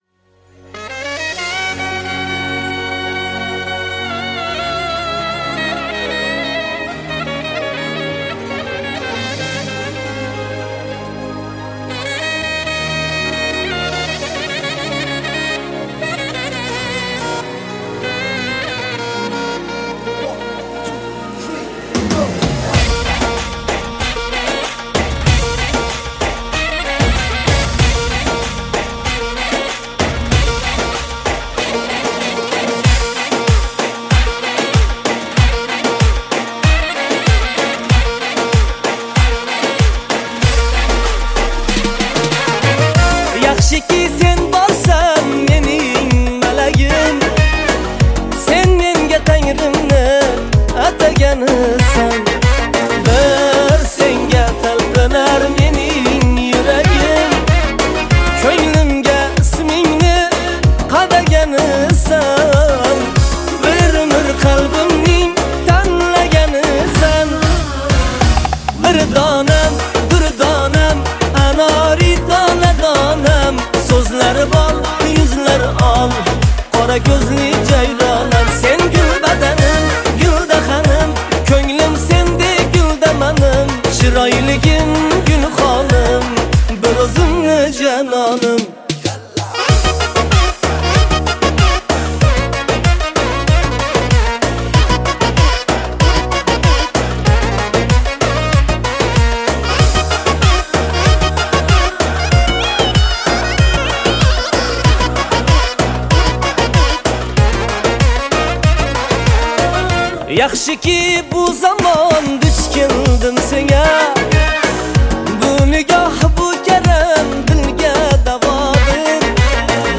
• Жанр: Турецкие песни